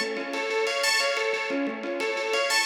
Index of /musicradar/shimmer-and-sparkle-samples/90bpm
SaS_Arp01_90-A.wav